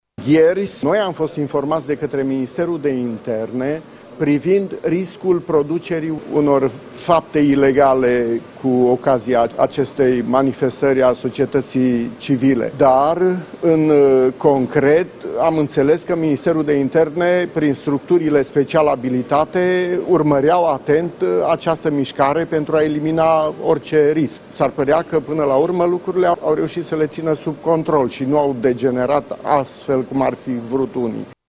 Augustin Lazăr a vorbit și despre incidentele de la protestele de aseară din Piața Victoriei: